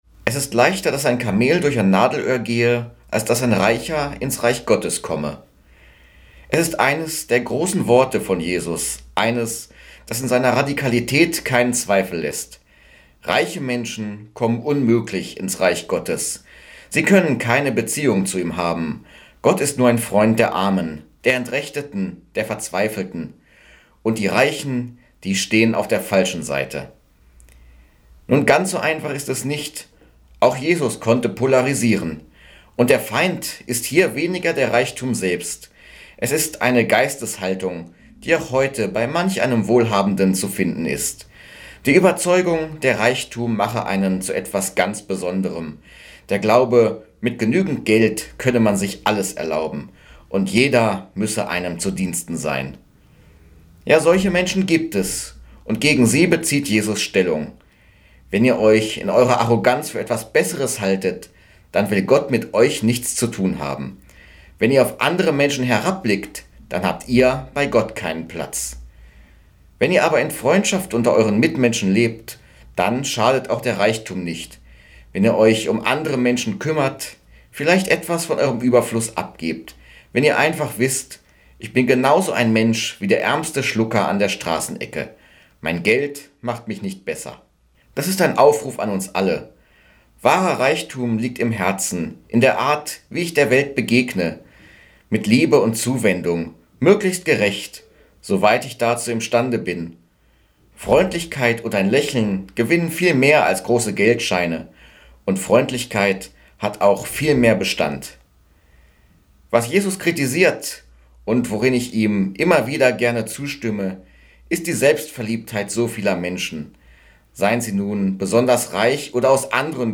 Radioandacht vom 5. Juli